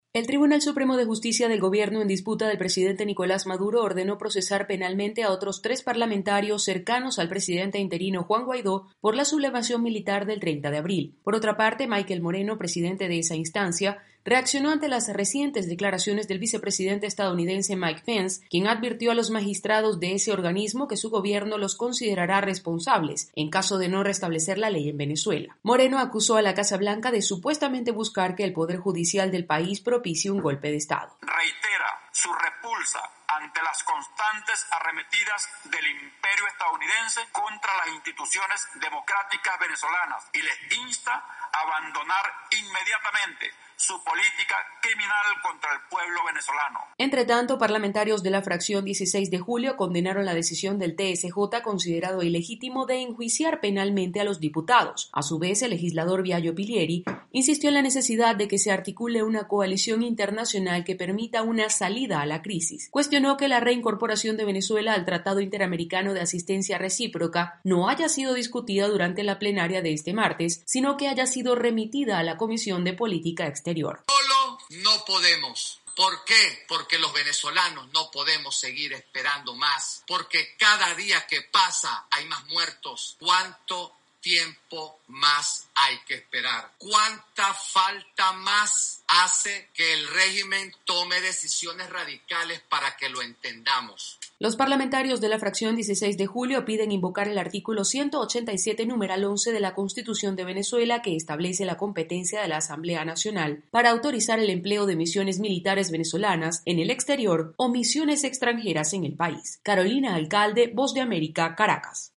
VOA: Informe desde Venezuela